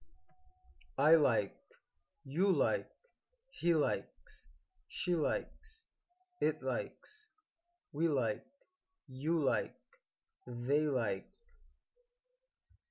Escutem a pronúncia e não se esqueçam de repetir depois do áudio, pois só é possível aprender a pronúncia correta das palavras ouvindo e copiando os sons reproduzidos corretamente.